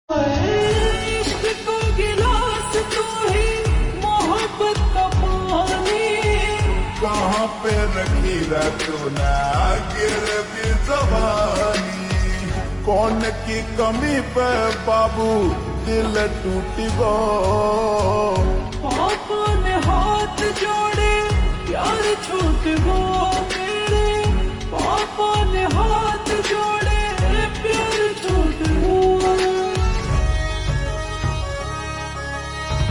Haryanvi Songs
(Slowed + Reverb)